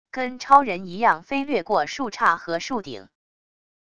跟超人一样飞掠过树杈和树顶wav音频